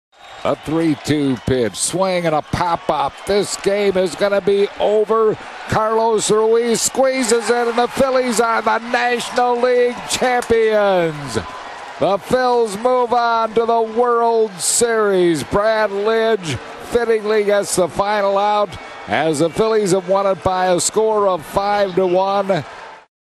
Harry Kalas makes the call